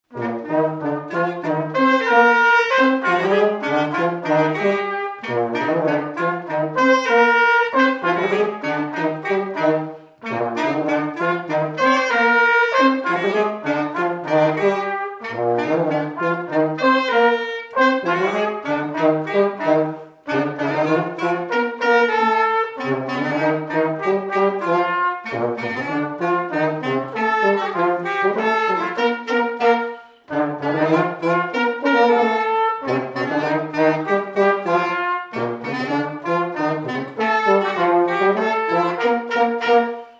Polka
instrumental
danse : polka
Pièce musicale inédite